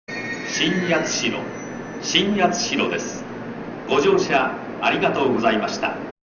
在来線の放送とは声・放送内容が大幅に異なっていますが、詳細であることには変わりありません。
ホームドア開閉時には、駅員が注意放送をする為、発車放送、到着案内放送では駅員放送が被ります。
到着放送　男声